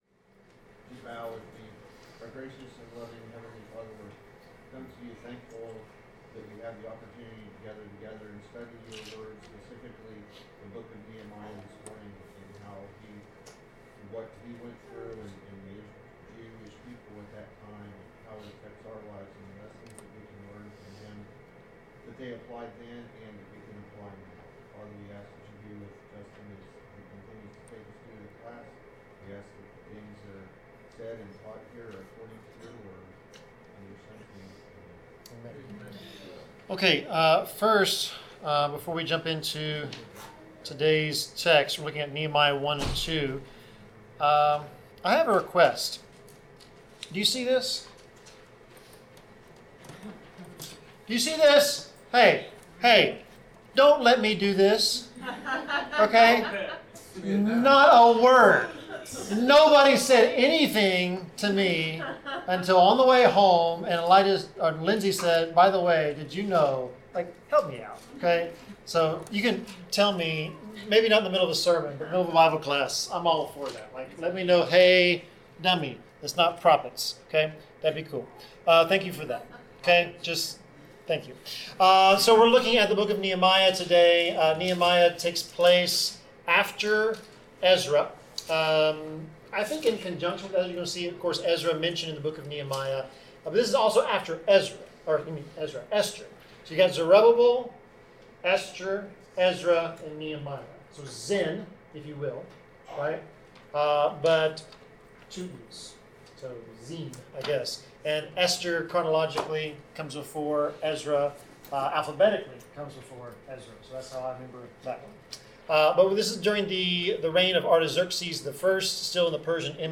Bible class: Nehemiah 1-2 (Let Us Rise Up and Build)
Service Type: Bible Class